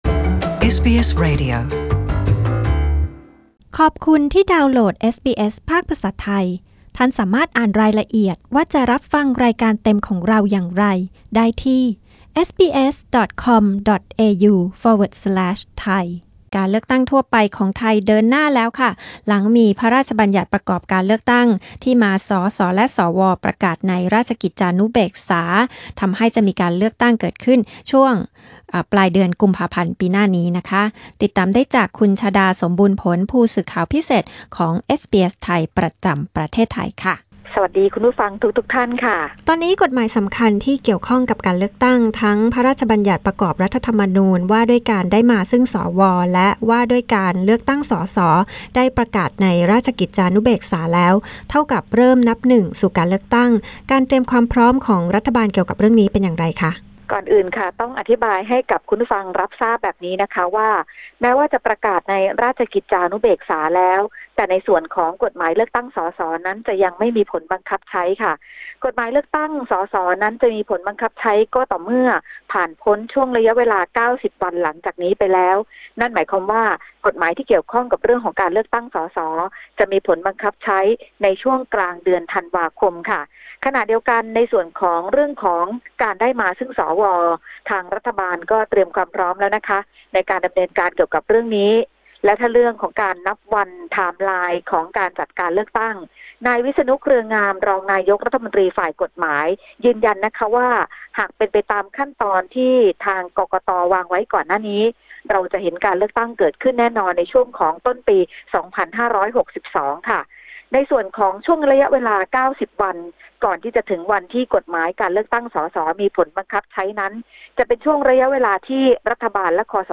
รายงานจากกรุงเทพมหานคร